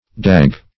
Dag \Dag\ (d[a^]g), n. [Cf. F. dague, LL. daga, D. dagge (fr.